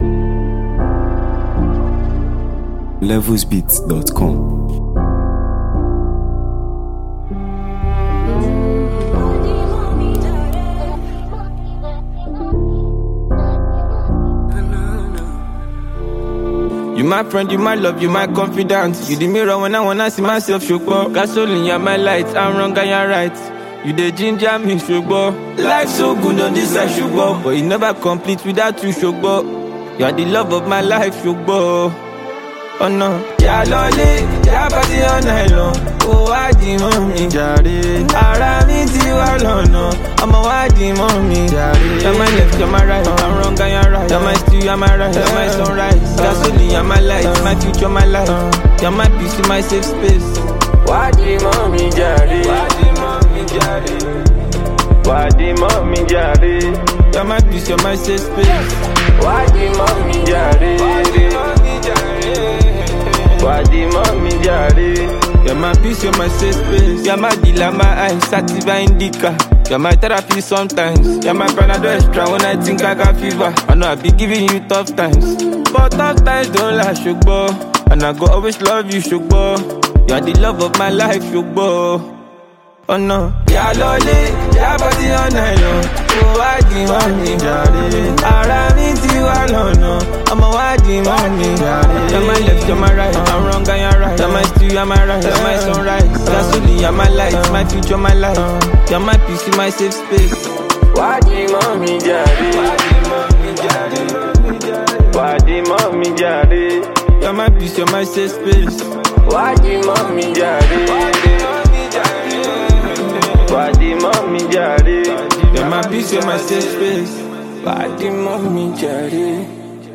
soothing and emotionally rich single